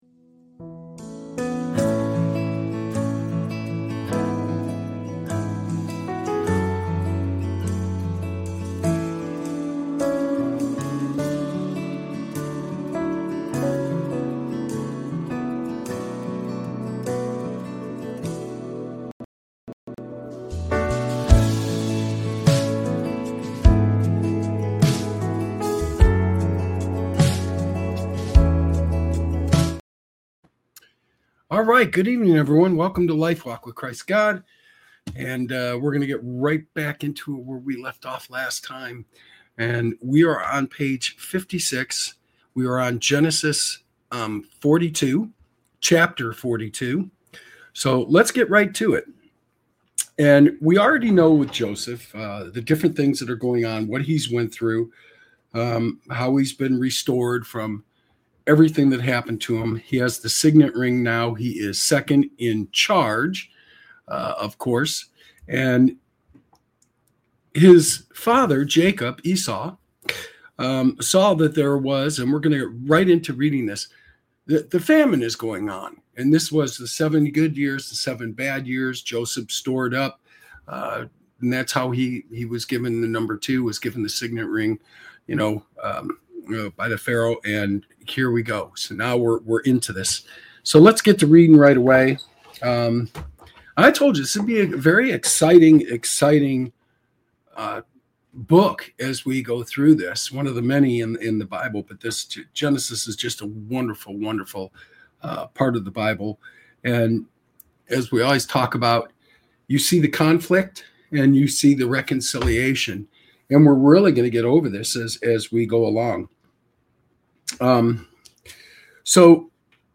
This show offers a reading into the teachings of Jesus Christ, providing insights into the Bible.